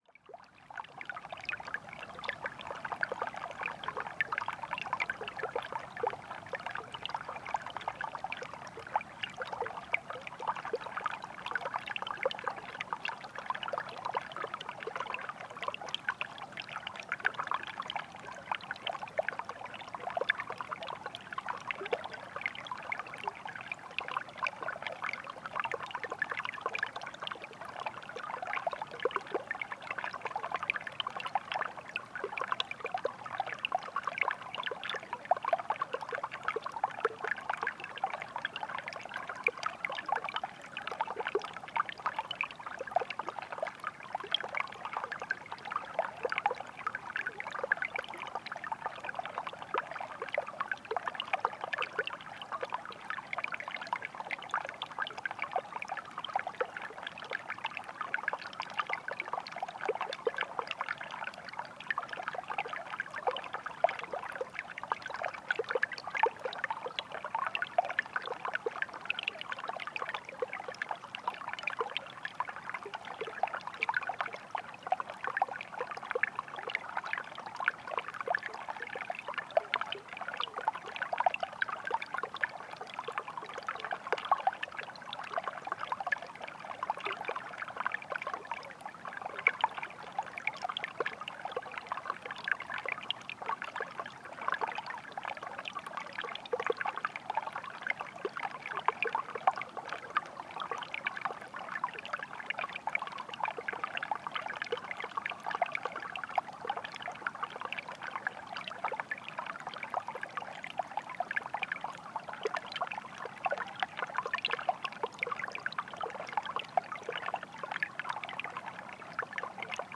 Frozen Stream | Mont-Tremblant National Park
In a calm winter forest, the soothing flow of a stream under a fine ice sheet.
Mont-Tremblant National Park, QC.